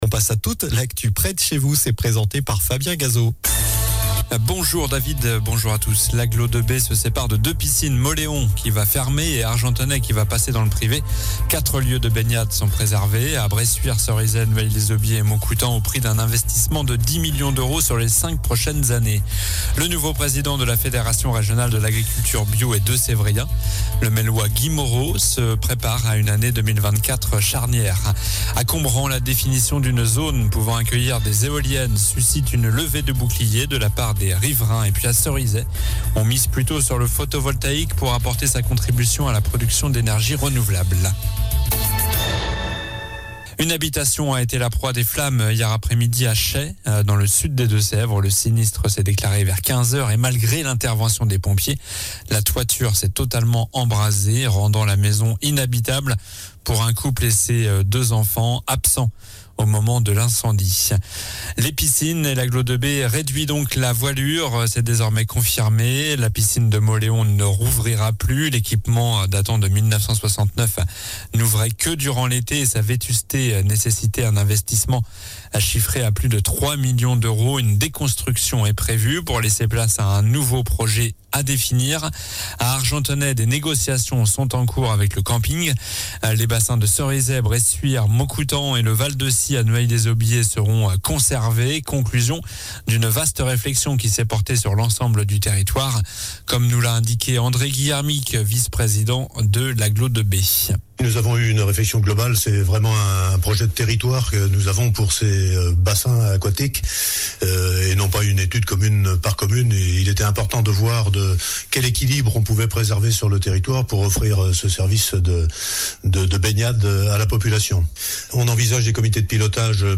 Journal du vendredi 22 décembre (midi)